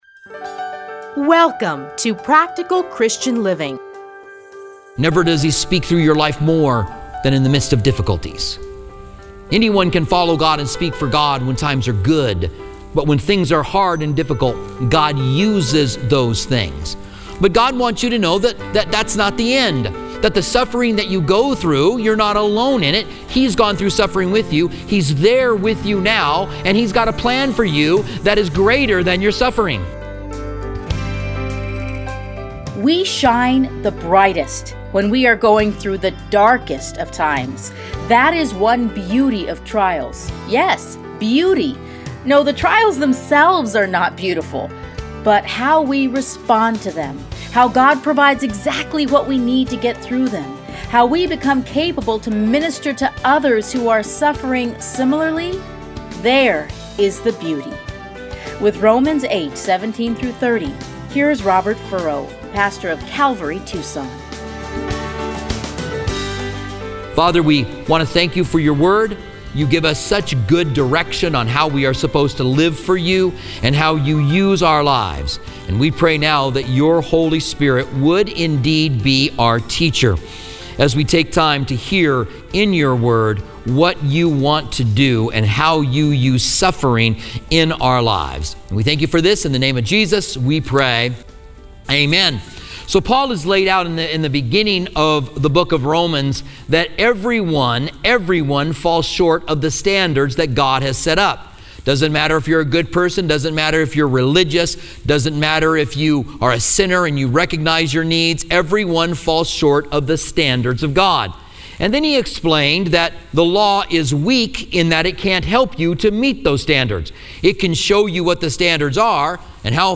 30-minute radio programs